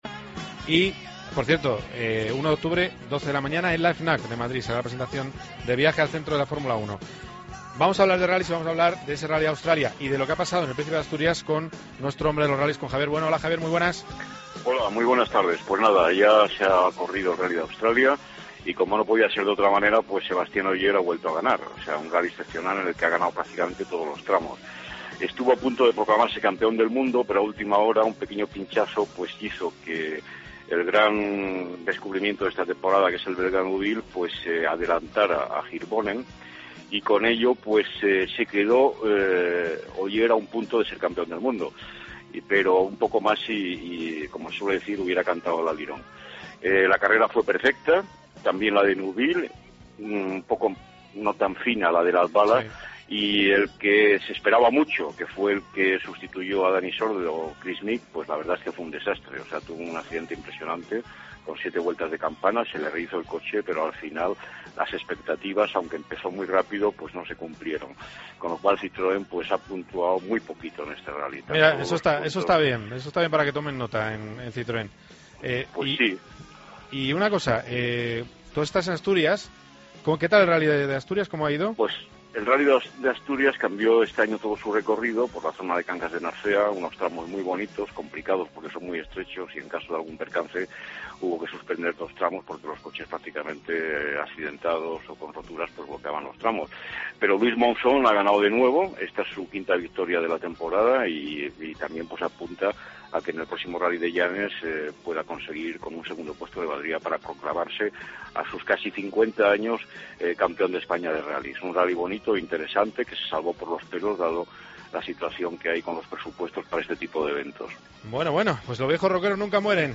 El piloto tres veces campeón del Campeonato Europeo de Camiones ha pasado por los micrófonos de COPE GP y ha dicho que: "las diferencias son mínimas, cualquier cosa creará distancias y tenemos que ir a por todas".